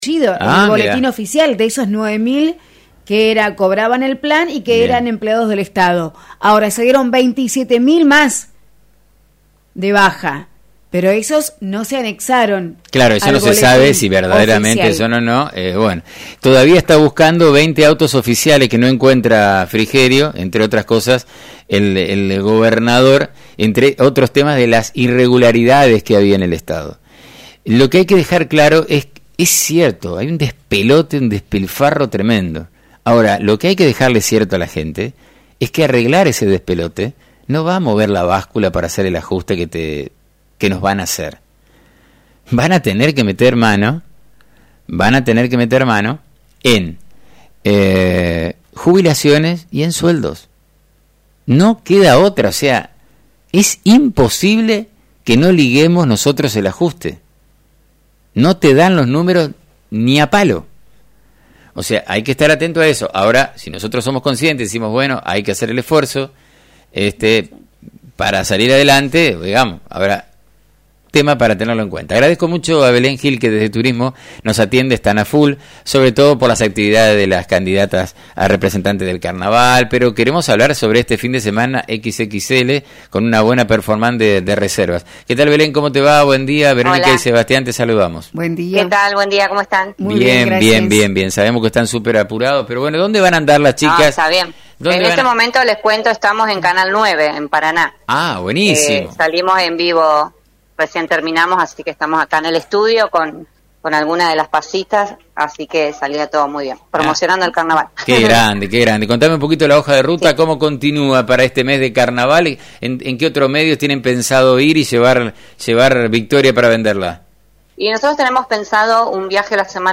La conversación culminó con la anticipación del pronóstico del tiempo y posibles cambios de fecha en caso de lluvia, mostrando la flexibilidad y compromiso con la experiencia del espectador.